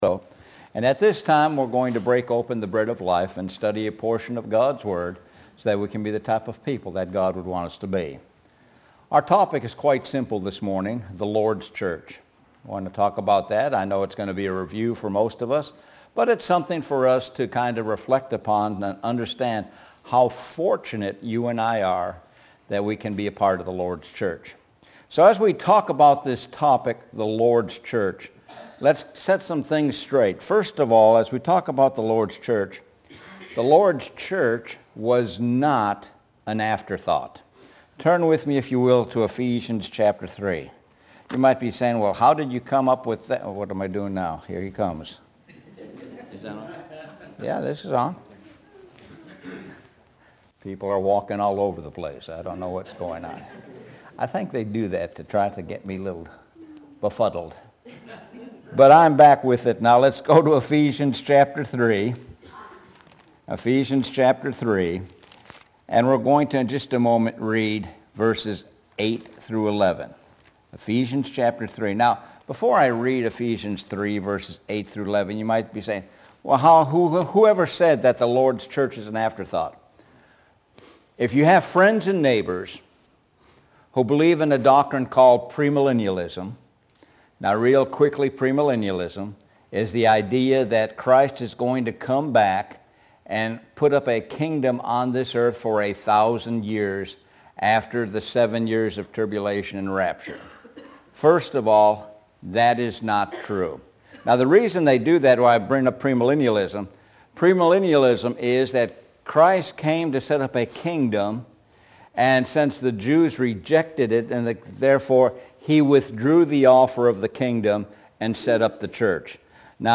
Sun AM Sermon – The Lords Church